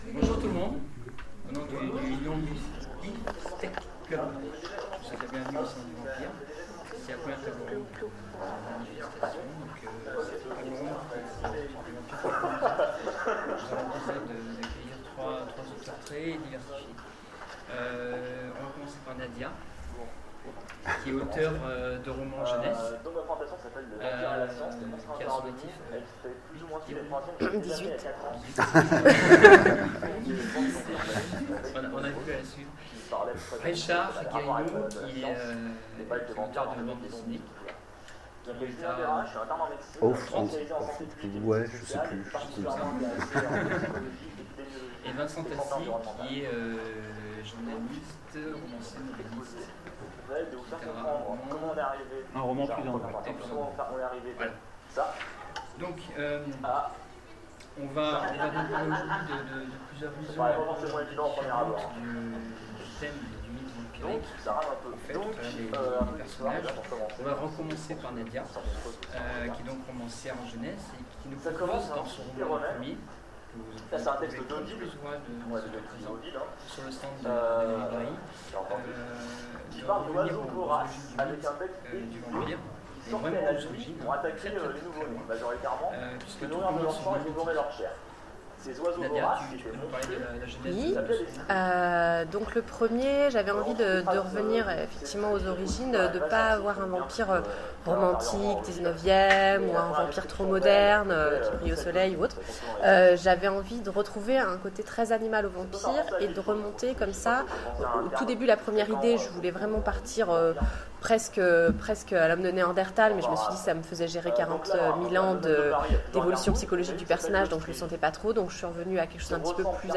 Salon du vampire 2016 : Conférence Vampires pas comme les autres